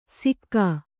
♪ sikkā